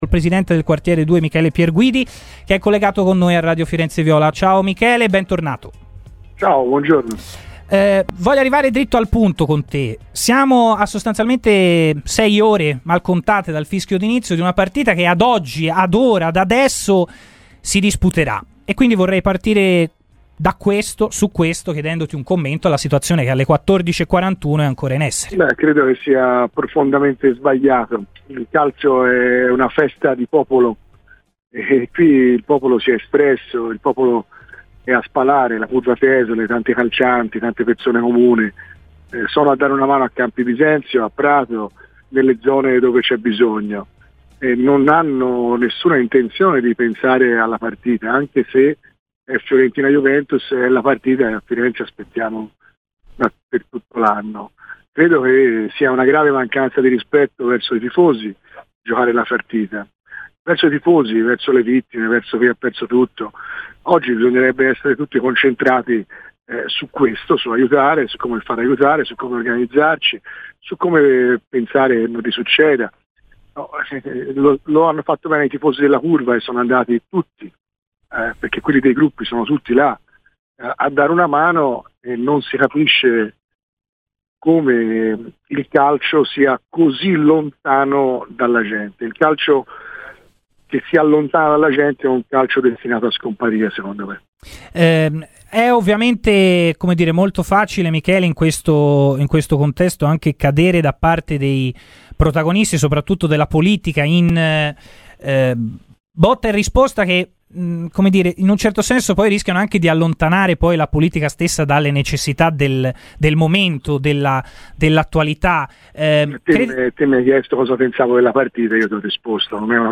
Il presidente del Quartiere 2 di Firenze Michele Pierguidi - in relazione alla scelta di confermare la partita tra Fiorentina e Juventus - intervenuto questo pomeriggio a RadioFirenzeViola.